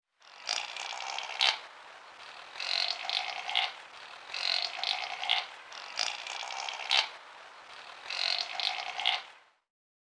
Здесь вы можете слушать и скачивать их естественные голоса: от блеяния молодых особей до предупредительных сигналов взрослых.
Звуки кавказской серны (caucasian chamois)